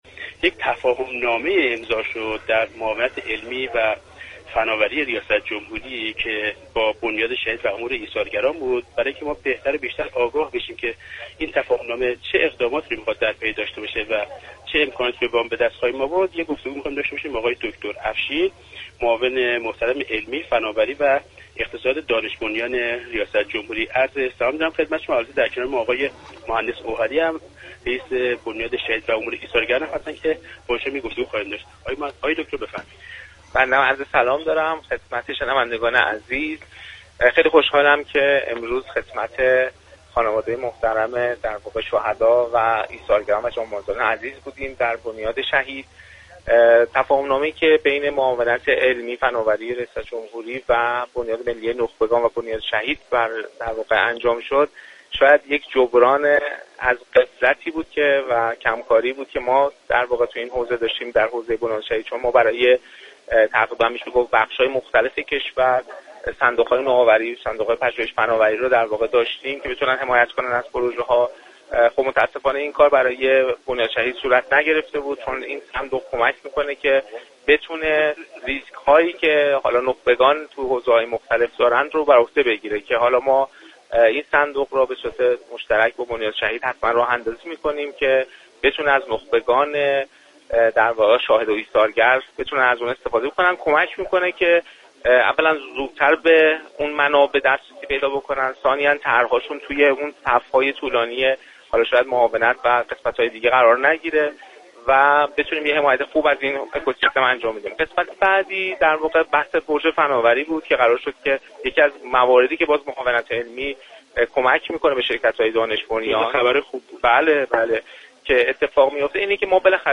در گفت و گو با گزارشگر برنامه «باغ فردوس»